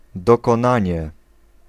Ääntäminen
IPA: [a.kɔ̃.plis.mɑ̃]